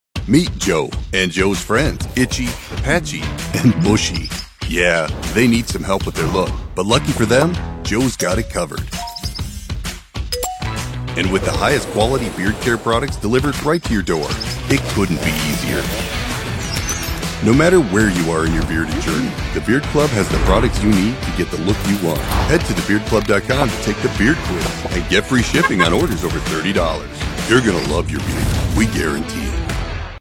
Everyday Dude Voiceover Cool, Charismatic laid-back liability.
Southern,Midwest
Middle Aged
Beard Club Promo Animation (128 kbps).mp3